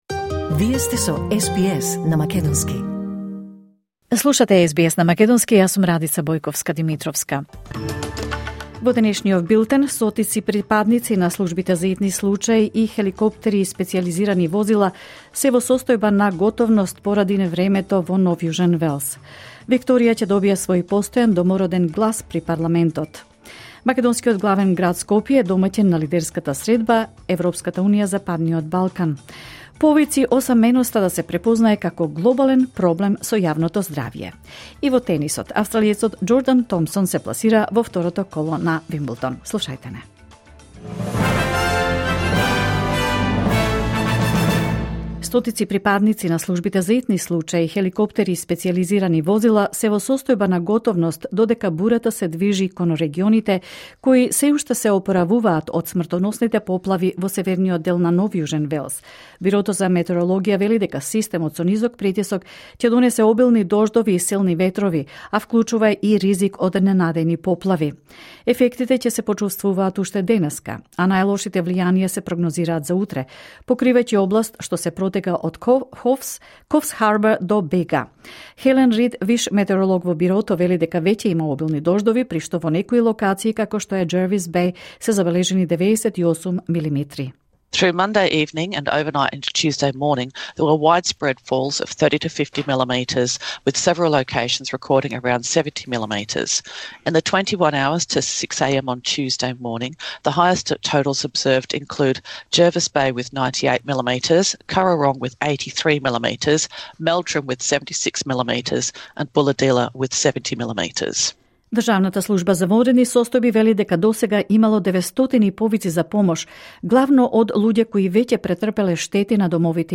Вести на СБС на македонски 1 јули 2025